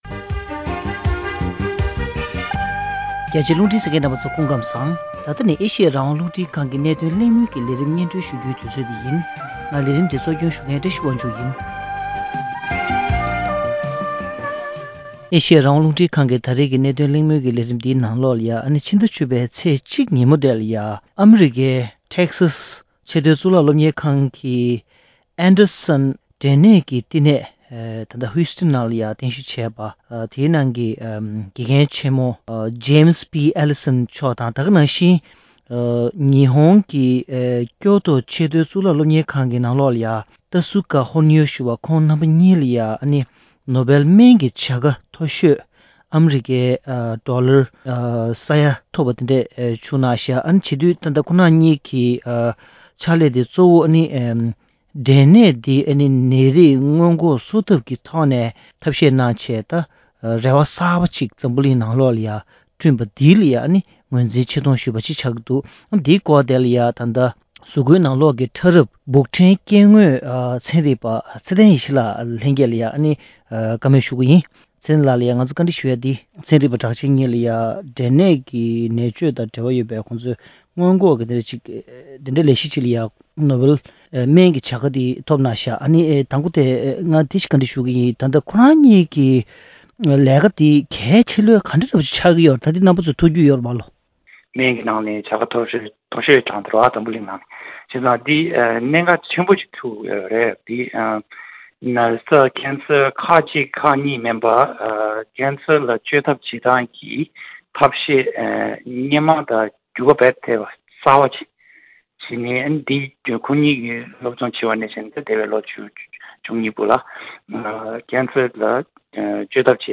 འབྲས་ནད་ཀྱི་བཅོས་ཐབས་གསར་པ་བརྙེད་པའི་ཐད་གླེང་མོལ།